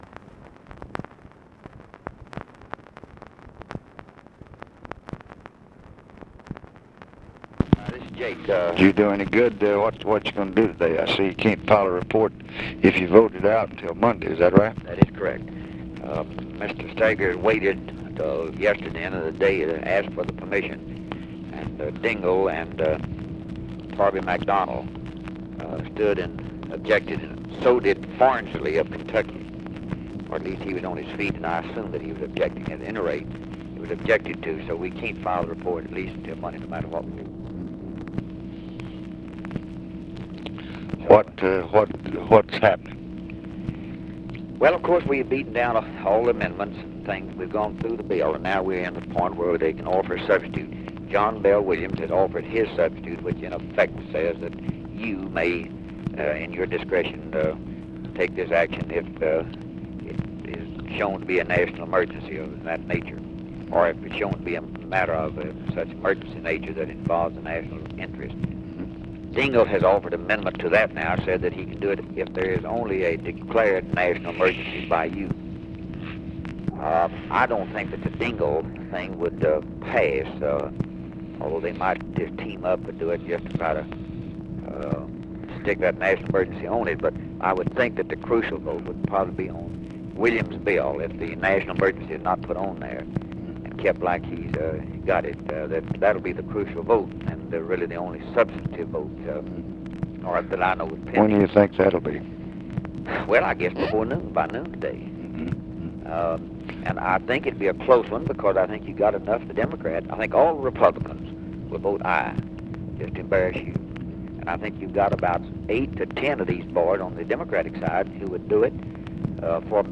Conversation with JAKE PICKLE, August 12, 1966
Secret White House Tapes